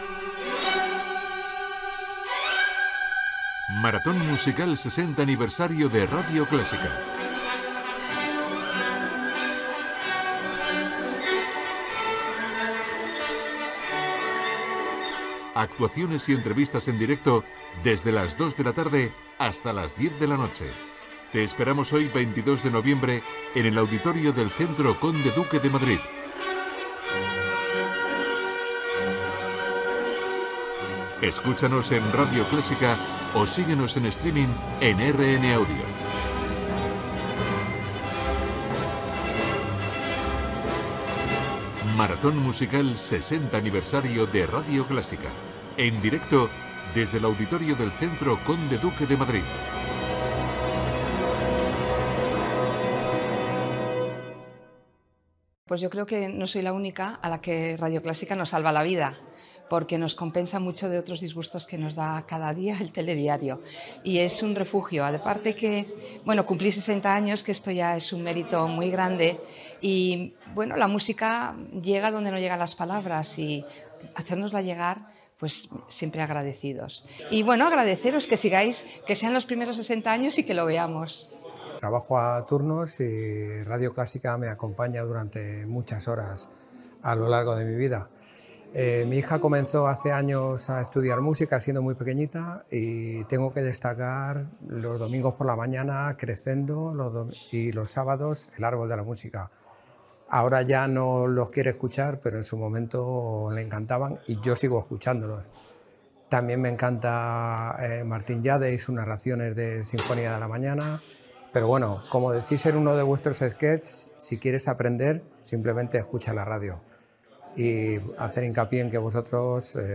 Espai especial fet des del Centro Conde Duque de Madrid. Indicatiu del programa, declaracions de cinc oïdors, dramatització sobre una famíia que escolta el Segundo Programa de Radio Nacional, Radio 2 i Radio Clásica. Presentació del programa especial, cant d'"Aniversari feliç". l'equip del programa, sorteig d'un disc, sintonia d'un del programa "A contraluz". Connexió fallida amb Radio 5